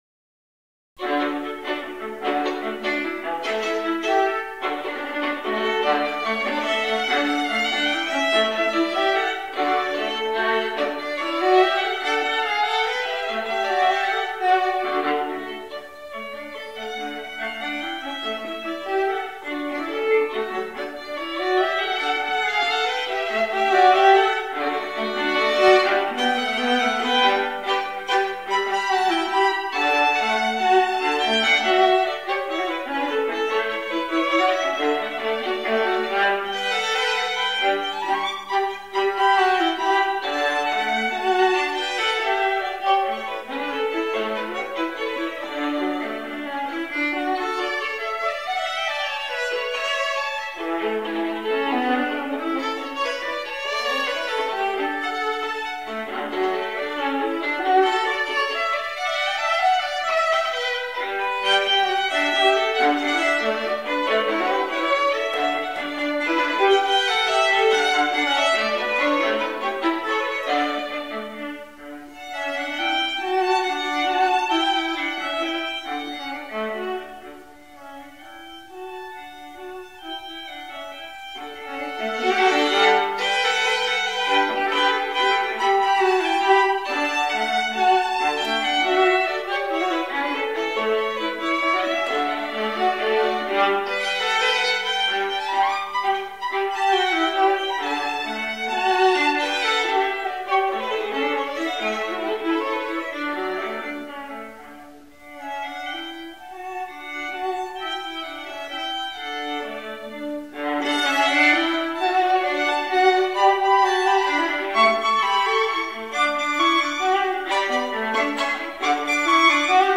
·        Mood: festive, lyrical, Latin, energetic
Violin, Viola